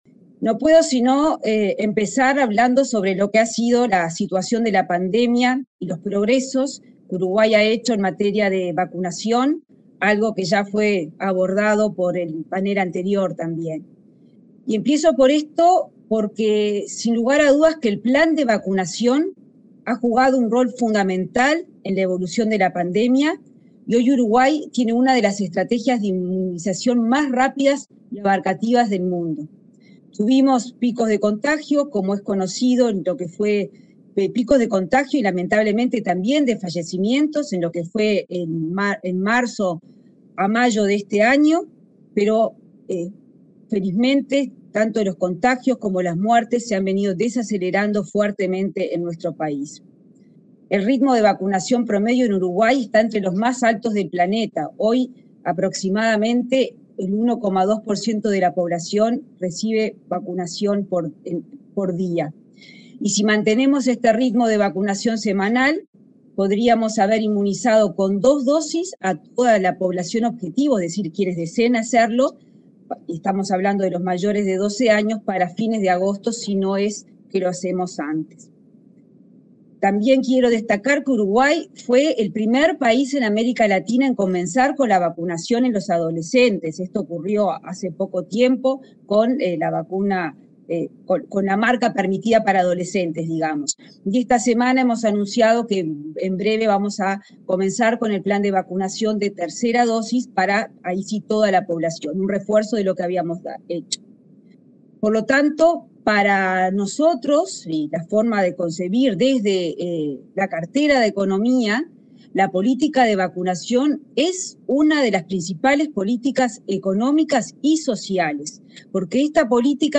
Intervención de Arbeleche en Conferencia sobre Oportunidades de Inversión en Tecnología en Uruguay
La ministra de Economía, Azucena Arbeleche, participó de la Conferencia sobre Oportunidades de Inversión en Tecnología en Uruguay convocada por la